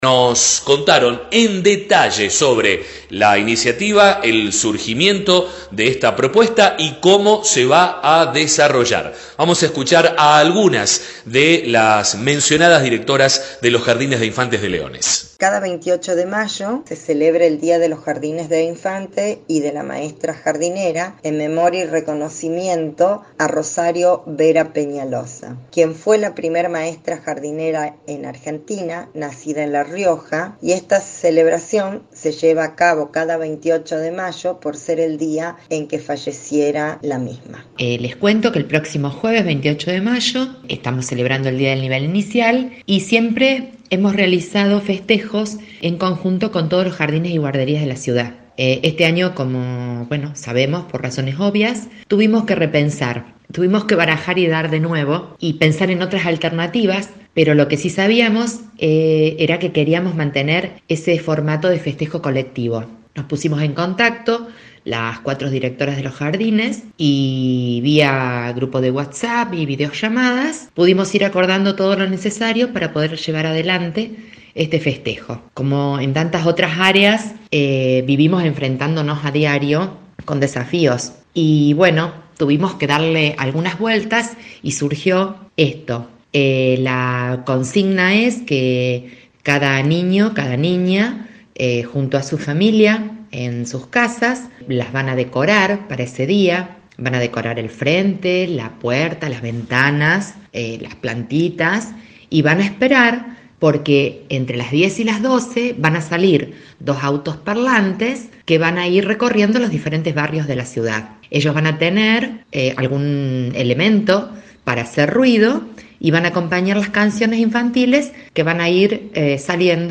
Escuchá la nota con las maestras.